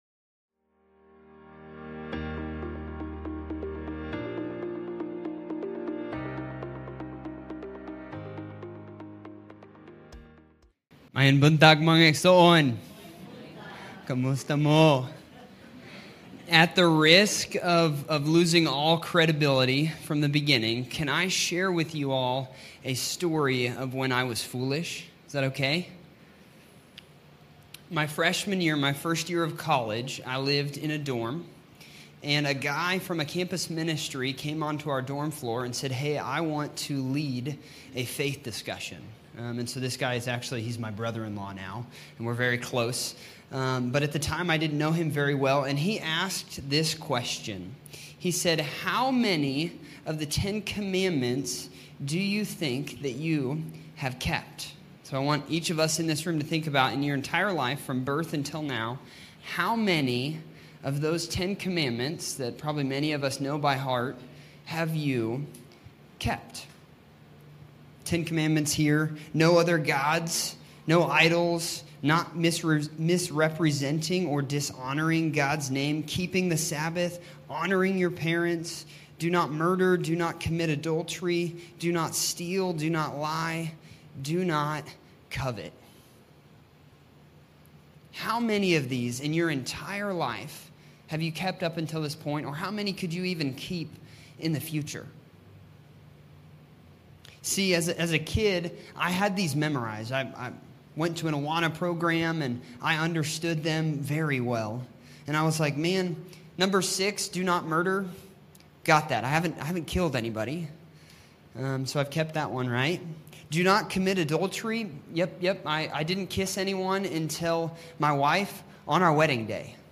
What the Law Could Never Give Galatians 3:1-14 Sermon By